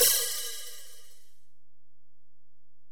Index of /kb6/Alesis_DM-PRO/HiHat
HiHat_Region_065.wav